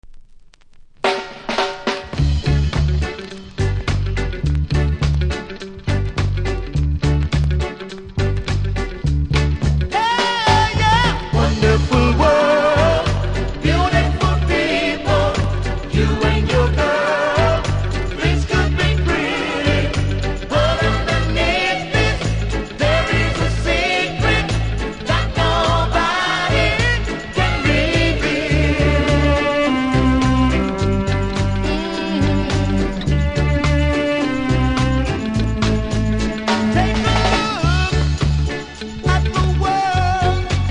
多少キズありますが音には影響せず良好です。